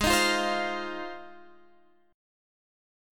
Ab6 Chord
Listen to Ab6 strummed